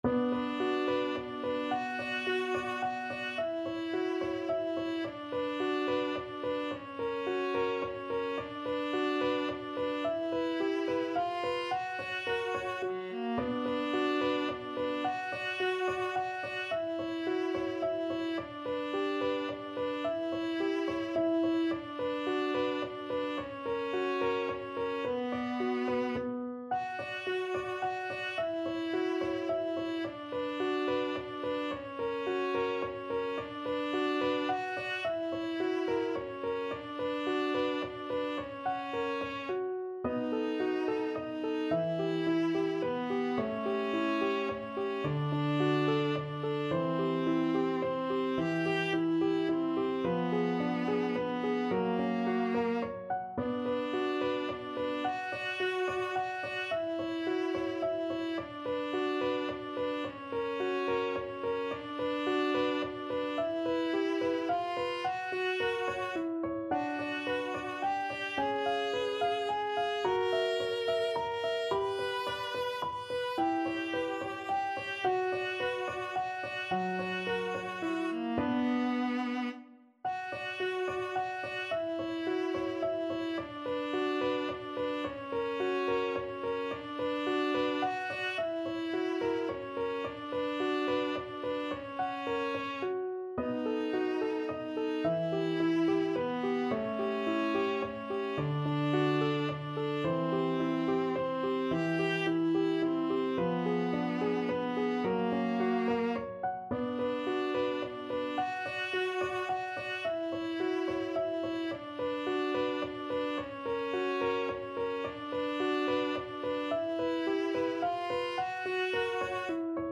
Viola
D major (Sounding Pitch) (View more D major Music for Viola )
= 108 Allegretto
3/4 (View more 3/4 Music)
Classical (View more Classical Viola Music)